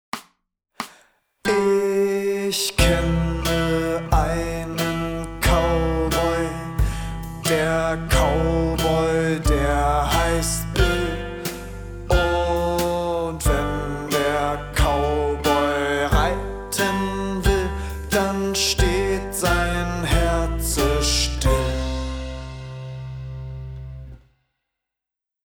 langsam